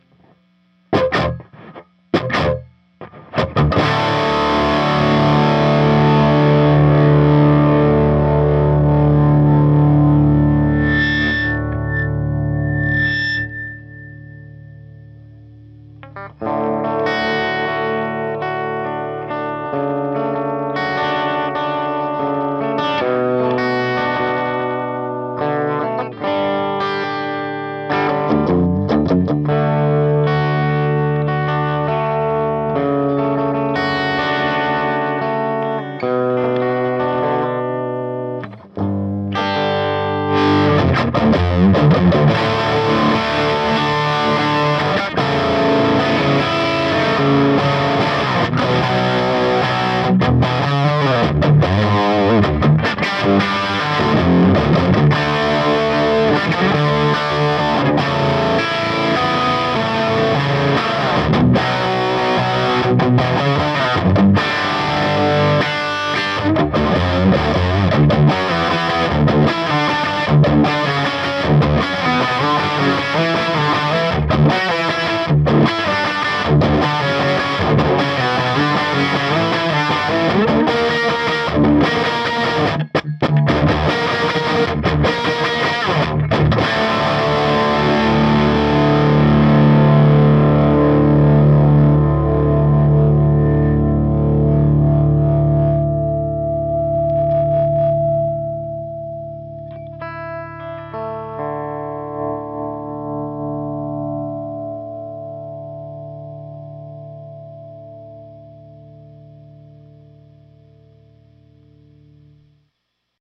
4. Les Paul Custom, Dirty, Bright Channel, l, 4-12 with G12-65
5. Les Paul Custom, Drop D, Bright Channel, l, 4-12 with G12-65